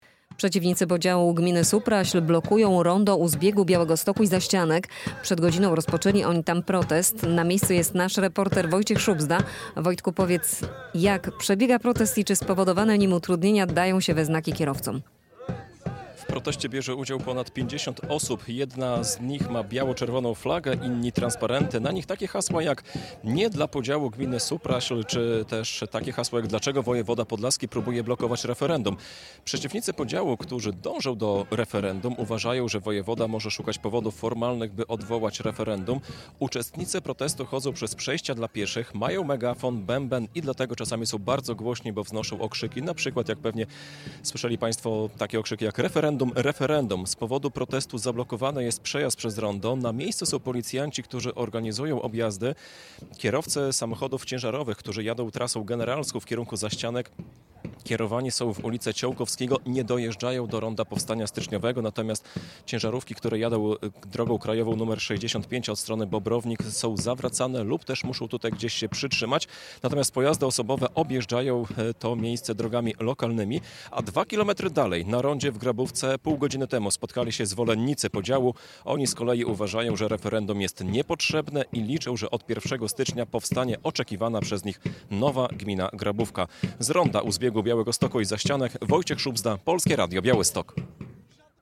Protest przeciwko podziałowi gminy Supraśl - relacja live